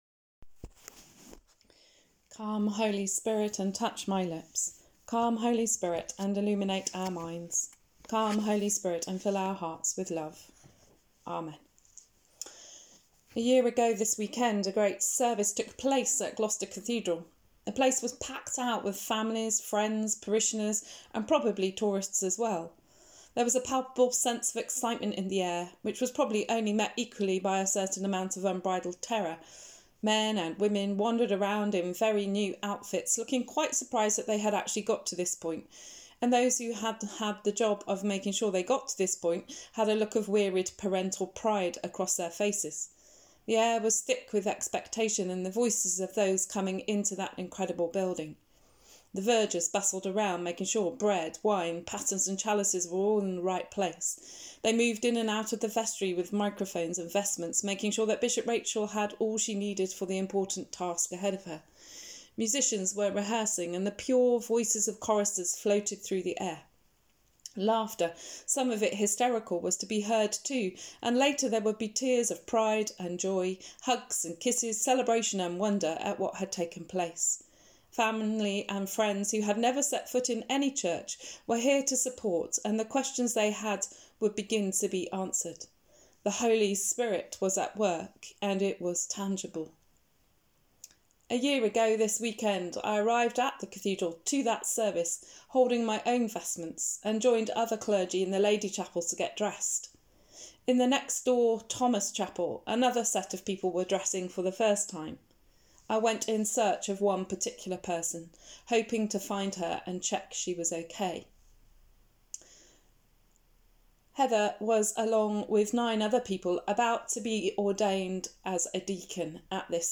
Sermon: Waiting in Mission | St Paul + St Stephen Gloucester